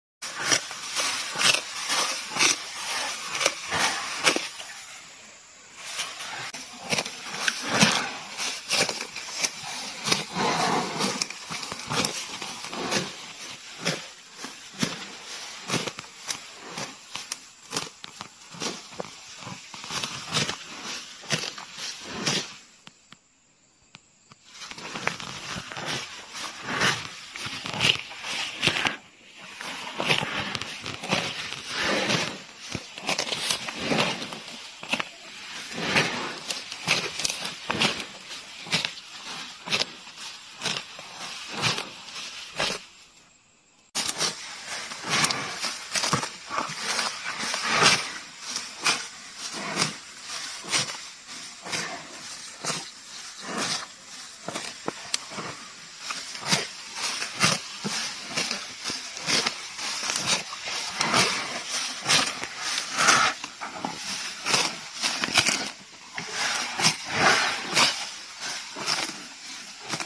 Grass & Cow Sound Effects Free Download